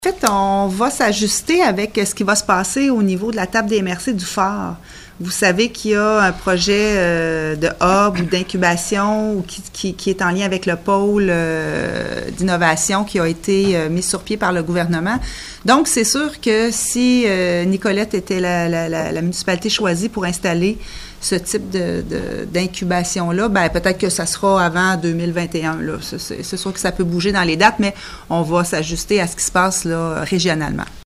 Et comme l’explique la mairesse Dubois, un incubateur industriel pourrait s’ajouter d’ici 2021